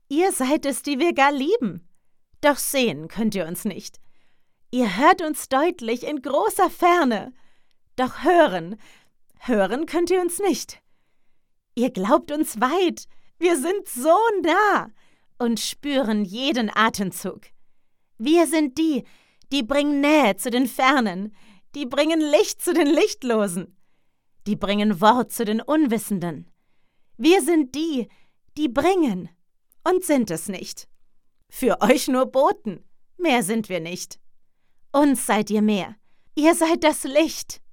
froehlich download file >>